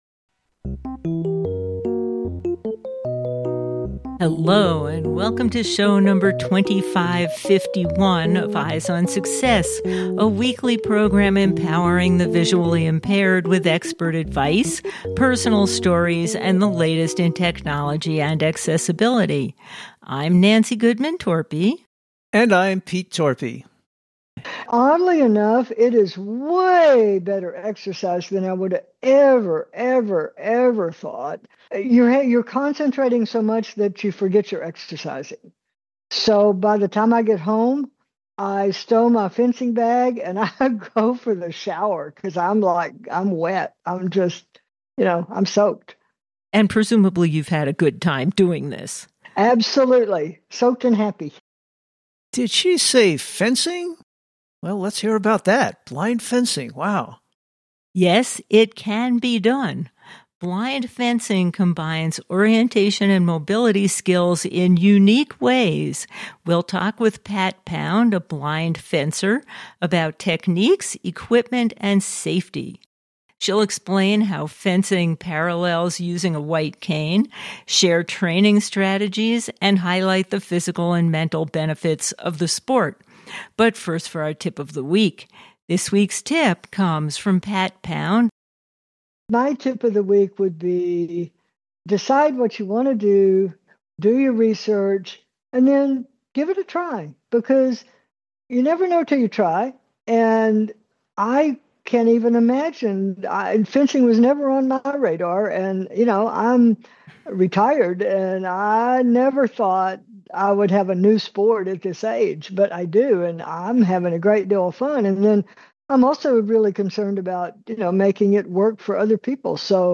a blind fencer